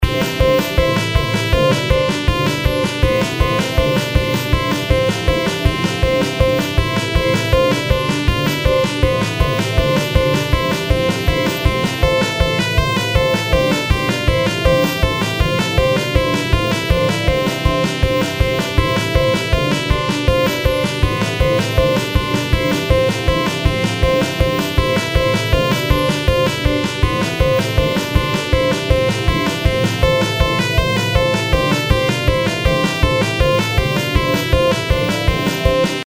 ショートポップ明るい
BGM